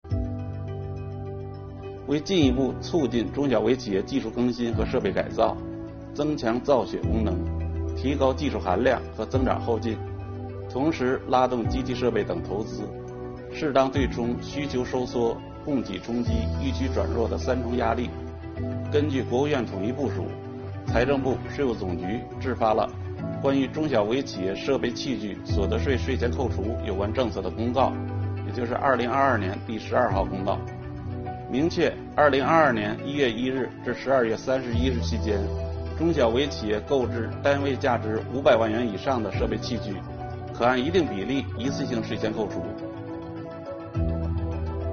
本期课程由国家税务总局所得税司一级巡视员刘宝柱担任主讲人，对中小微企业购置设备器具按一定比例一次性税前扣除政策进行详细讲解，便于征纳双方全面准确理解此项政策，统一政策执行口径。